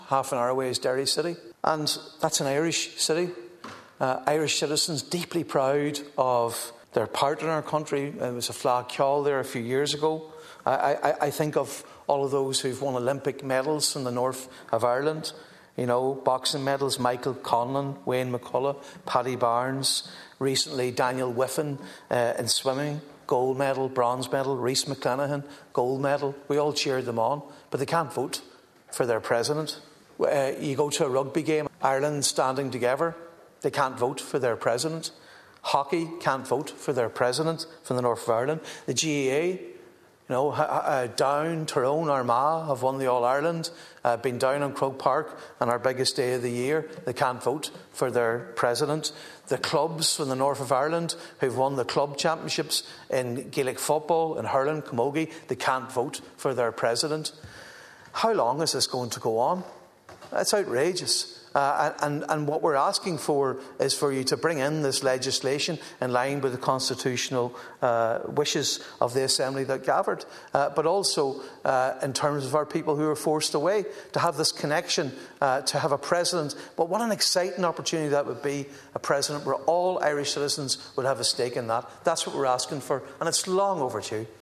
Speaking in the Dail yesterday evening, Sinn Féin TD Padraig Mac Lochlainn said people in the North have been contributing to achievements in Irish sport for years, yet cannot vote for President.